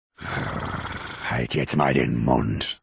Audiodialoge